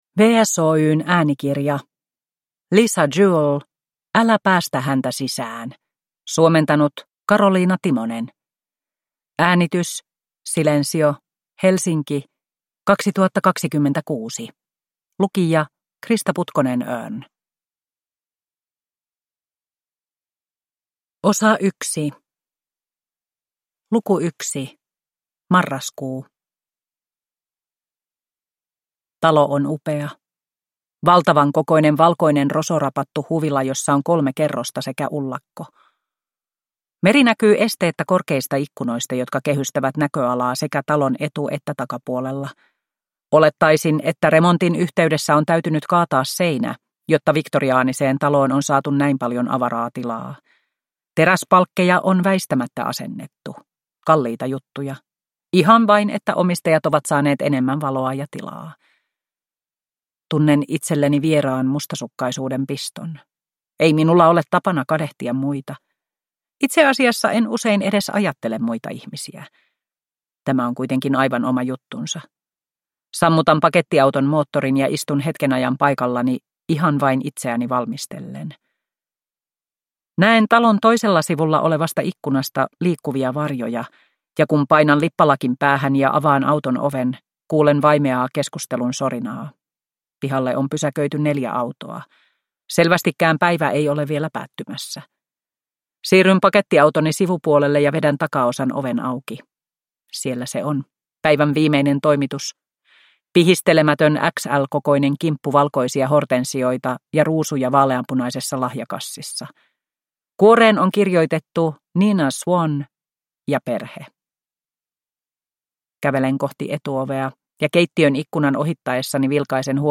Älä päästä häntä sisään (ljudbok) av Lisa Jewell